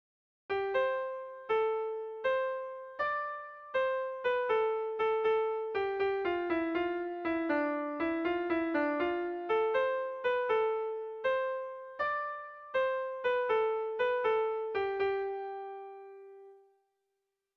Air de bertsos - Voir fiche   Pour savoir plus sur cette section
ABDE